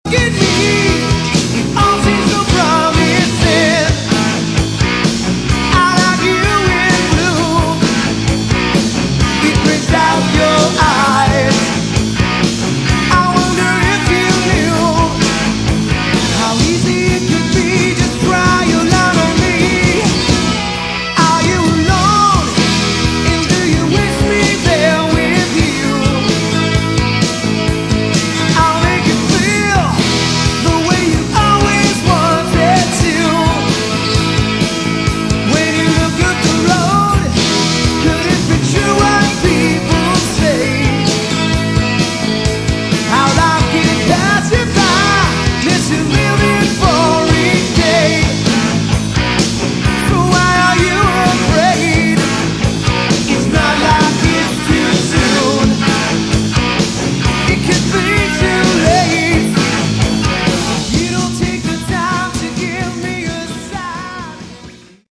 Category: AOR / Melodic Rock
lead vocals, keyboards, rhythm guitar
bass guitar, backing vocals
drums, percussion
lead guitar, spanish guitar, backing vocals